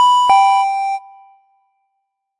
8 bit sounds " 门铃警报
描述：8位式门铃。
Tag: 警报 资产 8-b它 视频游戏